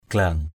/ɡ͡ɣla:ŋ/ (d.) khâu, vành = anneau de serrage. glang dhaong g*/ _D” khâu dao.